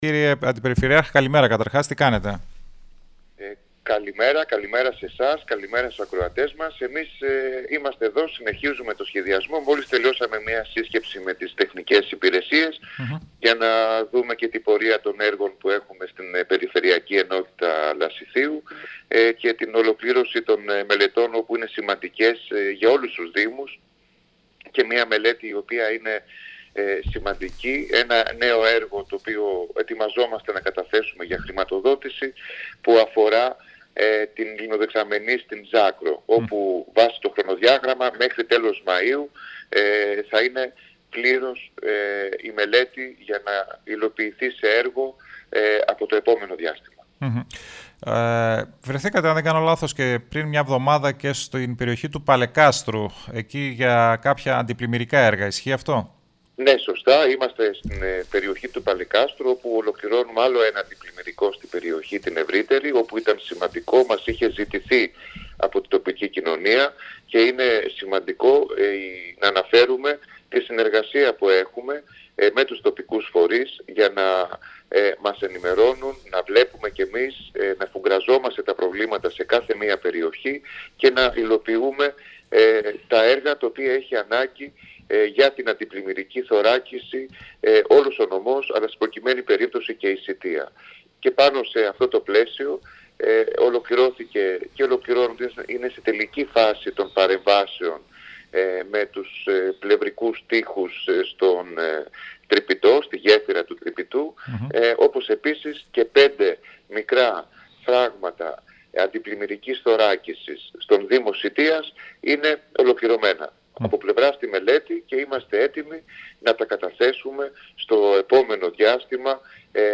Ο αντιπεριφερειάρχης Λασιθίου Γιάννης Ανδρουλάκης ανέλυσε στην πρωινή ζώνη του Style 100 την πορεία των έργων της περιφέρειας στο Λασίθι και την περιοχή της Σητείας(HXHTIKO)